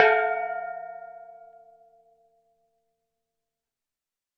打击乐 " 锣鼓 打击乐 05
描述：龚从各种大小的锣集合 录音室录音 Rode NT1000AKG C1000sClock音频C 009ERF边界麦克风 收割者DAW
标签： 敲击 敲击 金属 金属 中国 命中
声道立体声